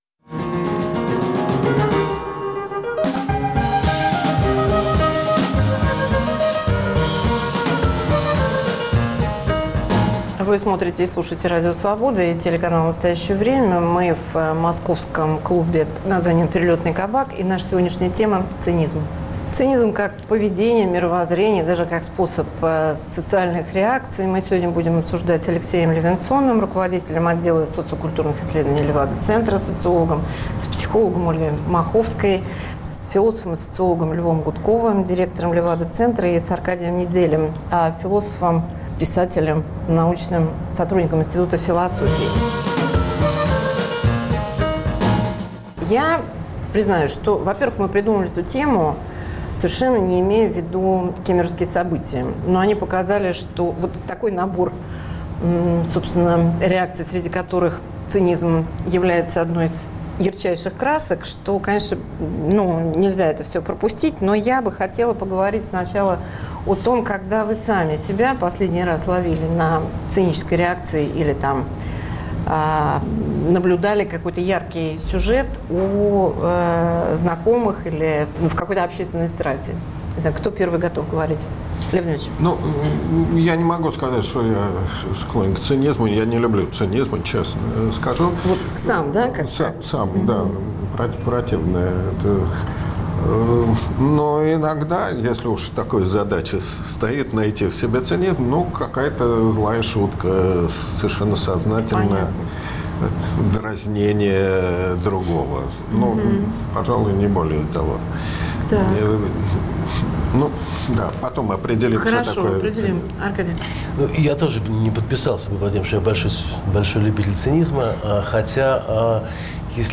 Цинизм как теория и практика личной и общественной жизни. Говорят социологи, философ, психологю